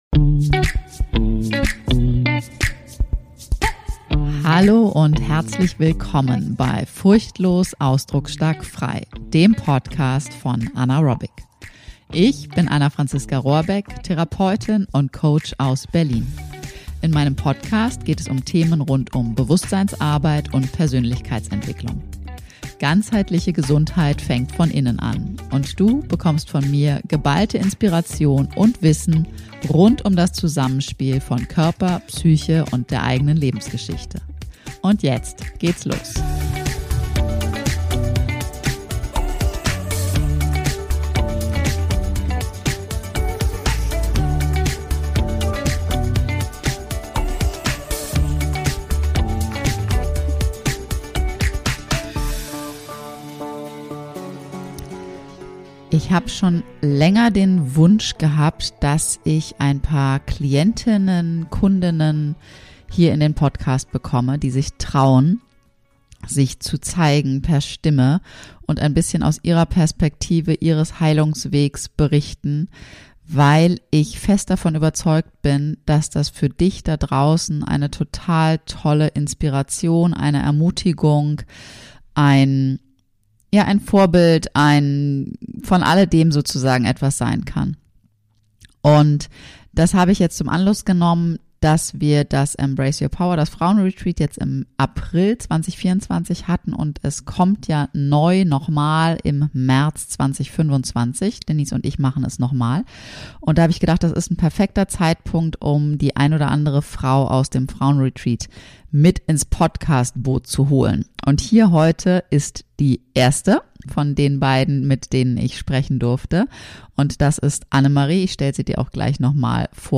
#111 Raus aus Ängsten - Mit Mut zur eigenen emotionalen Freiheit - im Gespräch